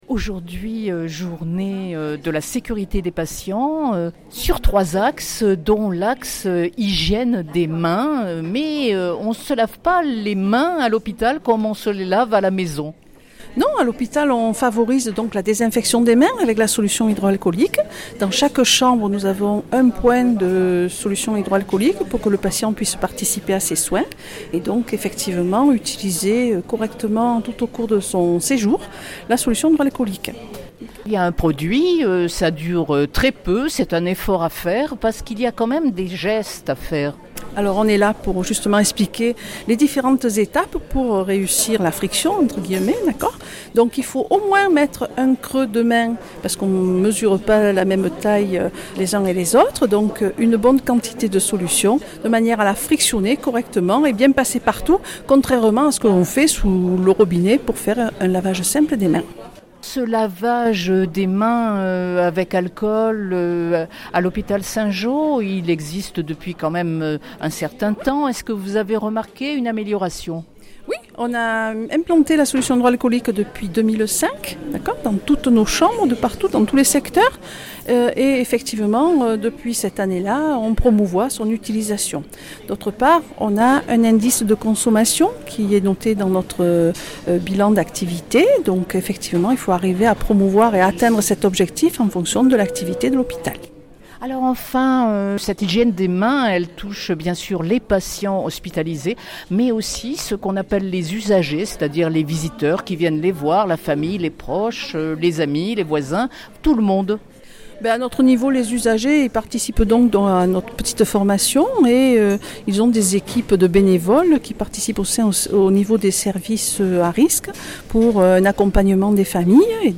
infirmière hygiéniste